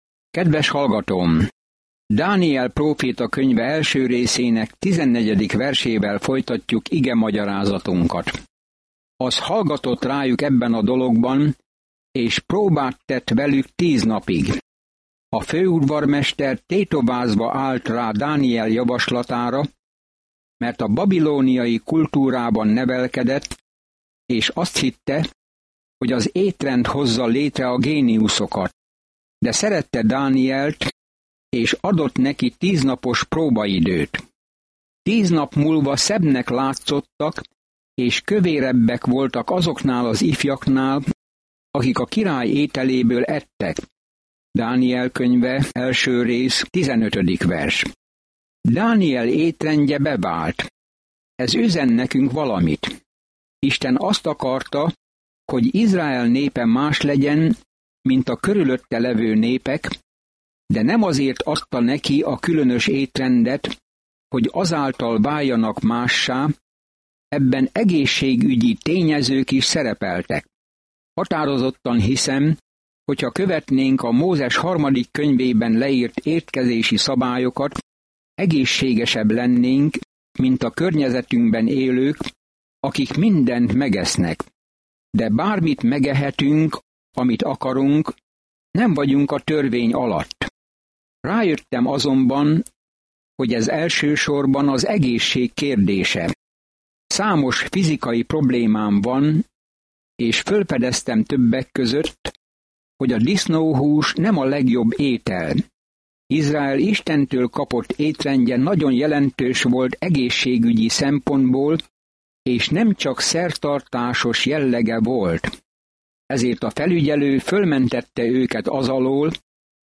Szentírás Dániel 1:14-21 Dániel 2:1-4 Nap 2 Olvasóterv elkezdése Nap 4 A tervről Dániel könyve egyszerre egy olyan ember életrajza, aki hitt Istenben, és egy prófétai látomás arról, hogy ki fogja végül uralni a világot. Napi utazás Dánielen keresztül, miközben hallgatod a hangos tanulmányt, és olvasol válogatott verseket Isten szavából.